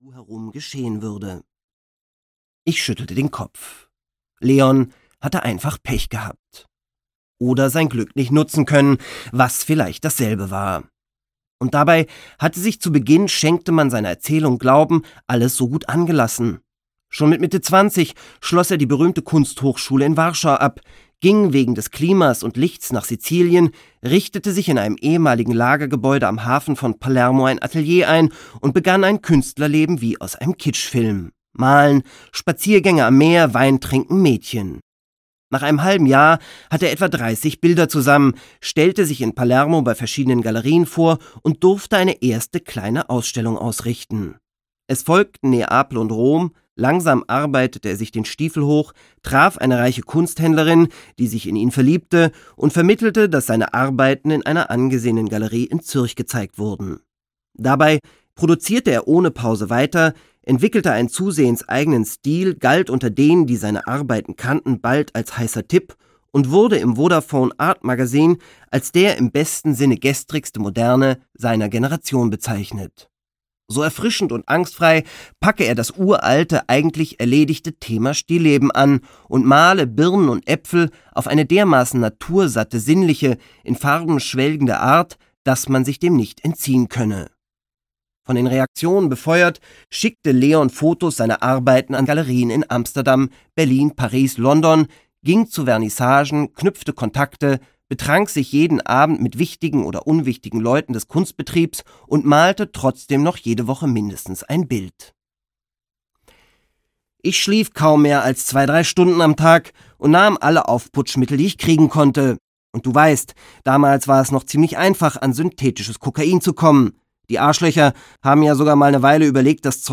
Chez Max - Jakob Arjouni - Hörbuch